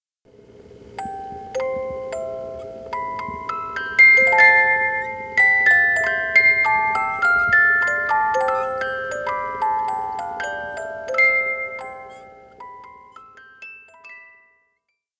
曲目：トロイメライ（シューマン）（22弁ムーブメント）
以下のサンプル音は２００８年ベル「トロイメライ」の出だしの１フレーズです
２００８年ベル当店音質改善後の音
(このサンプル音は標準的な１台を無作為に選び同一メカの改善前後を同一の環境で録音しています)
オリジナルでは最初の音がつまりぎみですが改善後のように音を素直に出すよう改良します
reu_bell_2008_after_3.wav